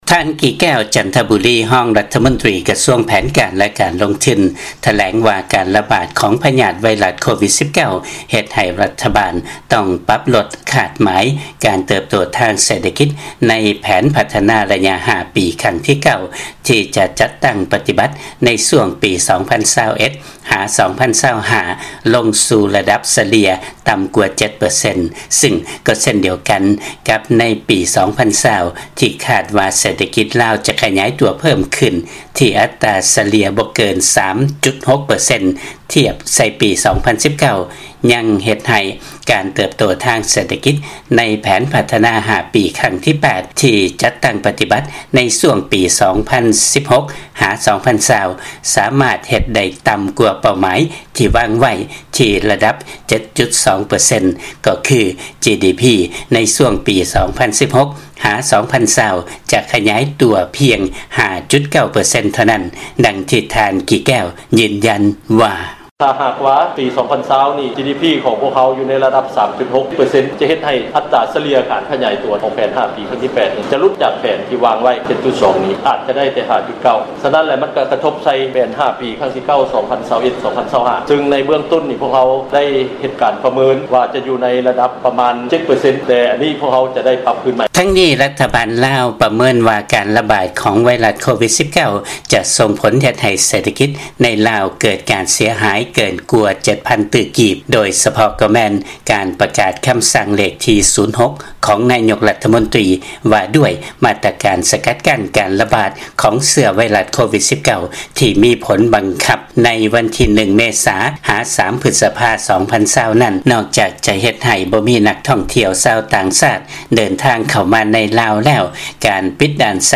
ຟັງລາຍງານ ລັດຖະບານ ລາວ ປັບຫຼຸດຄາດໝາຍ ການເຕີບໂຕທາງເສດຖະກິດ ໃນແຜນພັດທະນາ 5 ປີຄັ້ງທີ 8 ລົງຈາກເດີມ ຍ້ອນ Covid-19